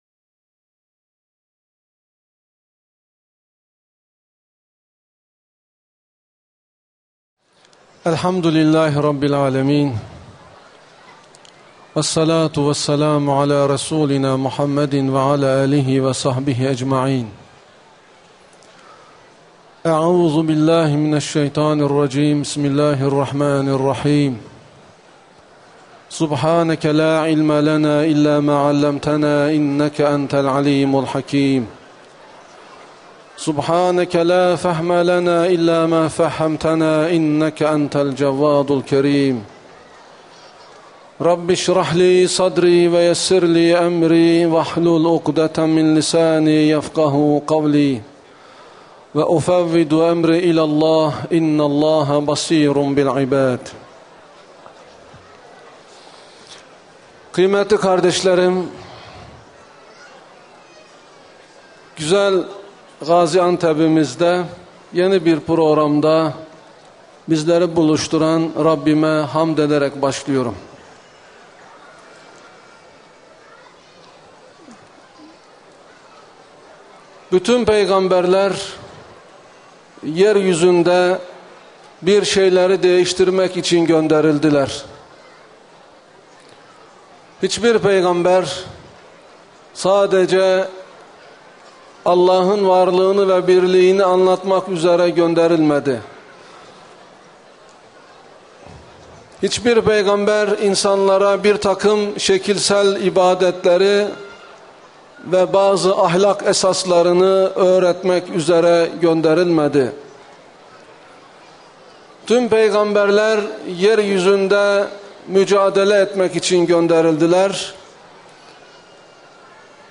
Konferans Bölümü